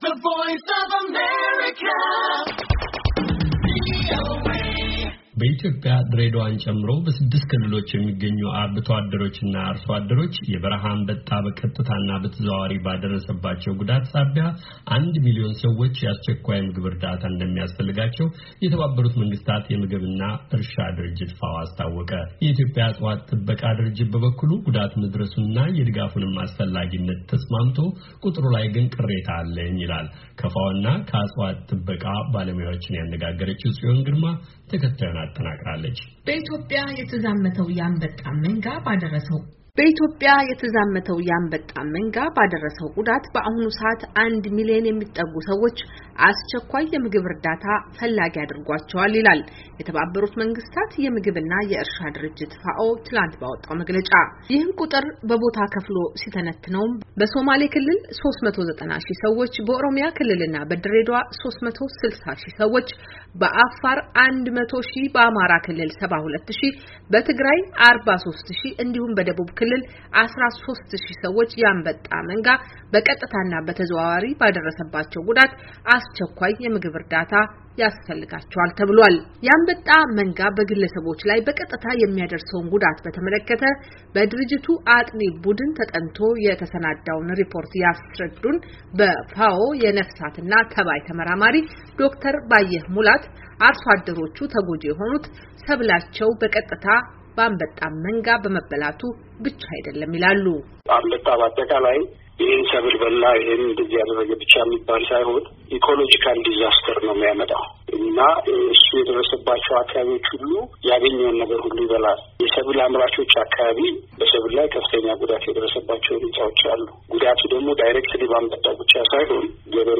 ከፋኦ እና ከእፅዋት ጥበቃ ባለሞያዎችን ያነጋገረችው